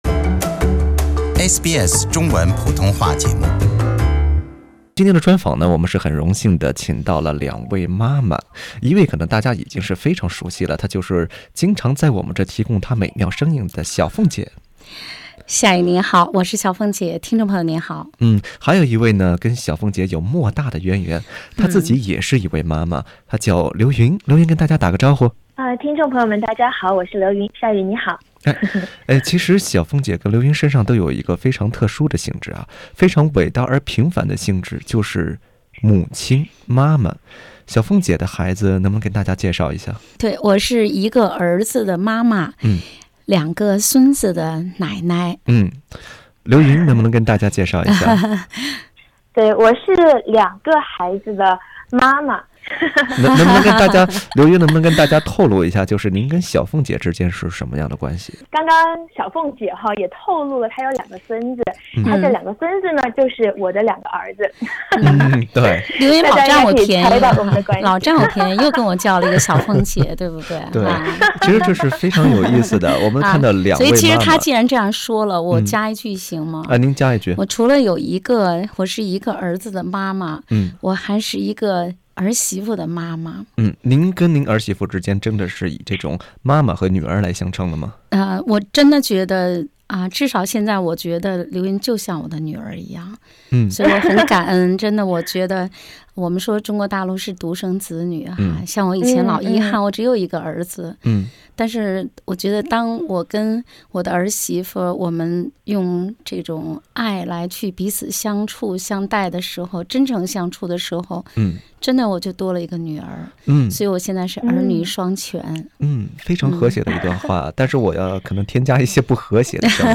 【专访】母亲节特辑-妈妈和奶奶的故事